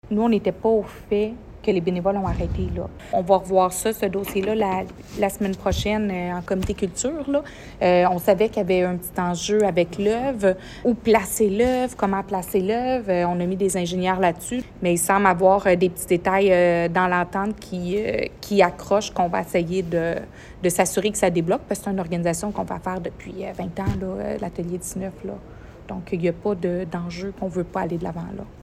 La mairesse de Granby, Julie Bourdon, explique vouloir que la situation dans ce dossier se résorbe rapidement :